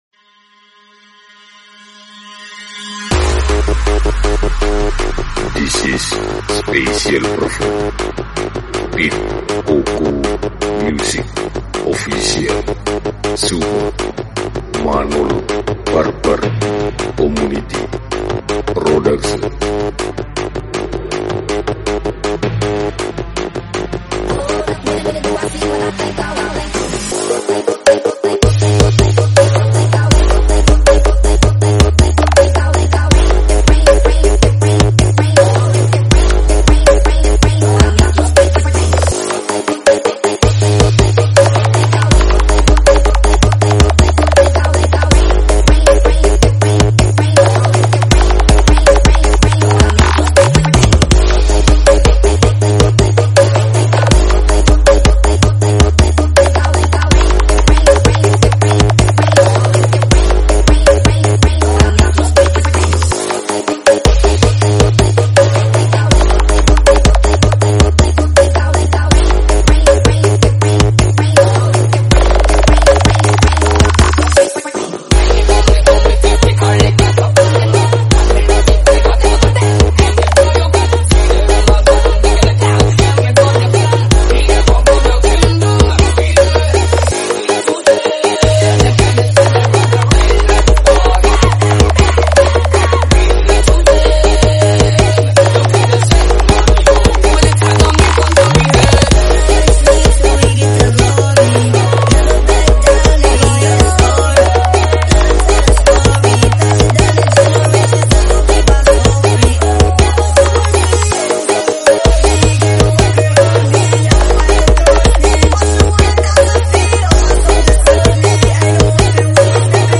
nrotok bass